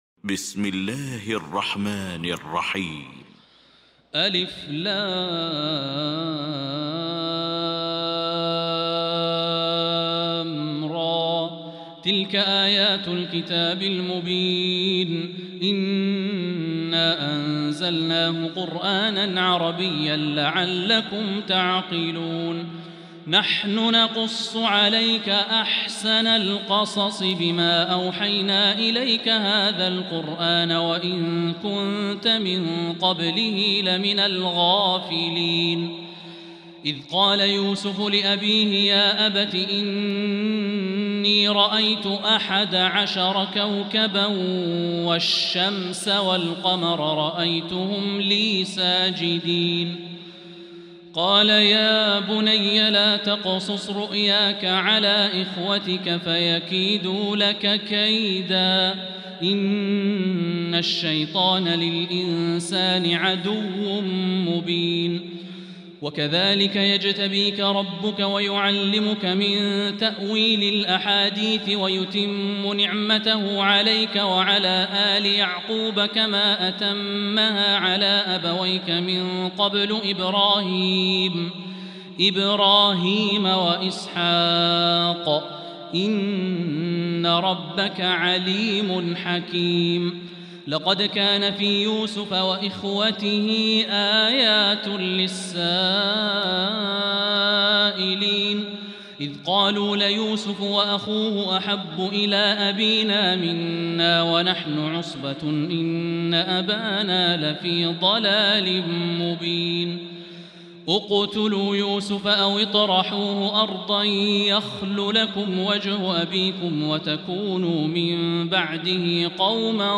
المكان: المسجد الحرام الشيخ: فضيلة الشيخ د. الوليد الشمسان فضيلة الشيخ د. الوليد الشمسان معالي الشيخ أ.د. عبدالرحمن بن عبدالعزيز السديس فضيلة الشيخ ياسر الدوسري يوسف